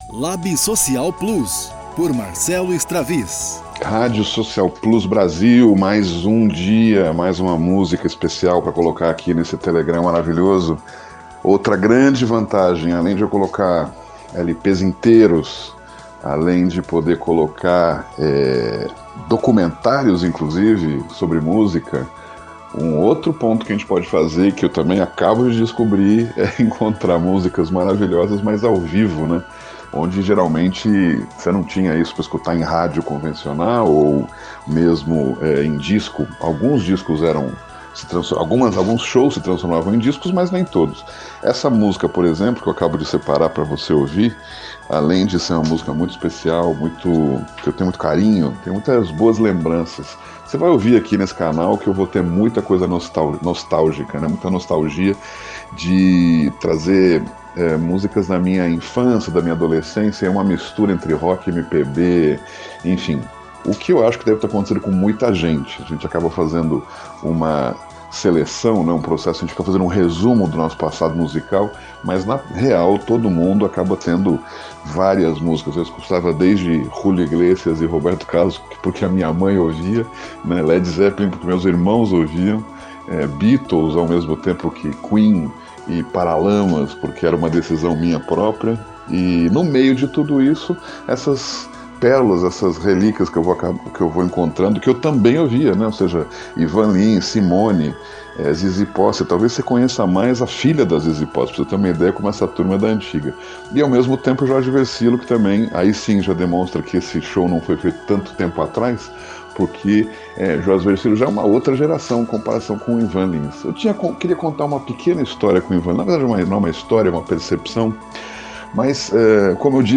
uma versão feita ao vivo